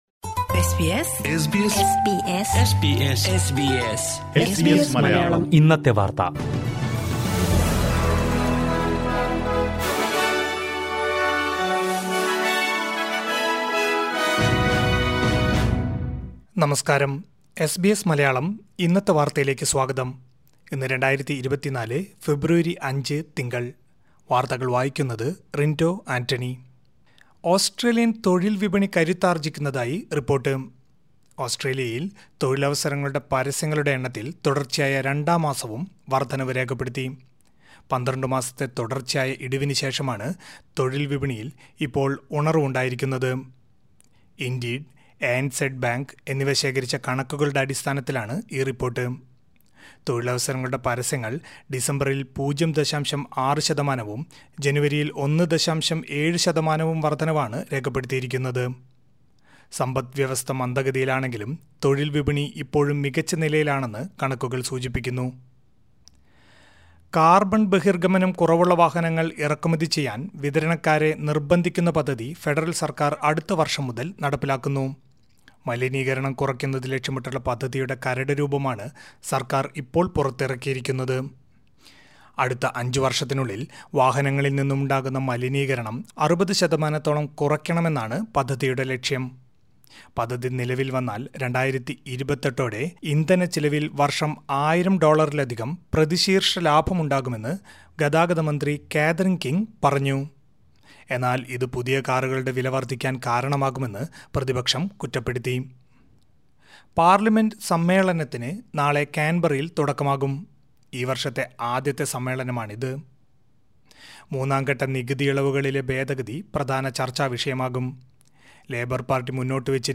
2024 ഫെബ്രുവരി അഞ്ചിലെ ഓസ്ട്രേലിയയിലെ ഏറ്റവും പ്രധാന വാർത്തകൾ കേൾക്കാം...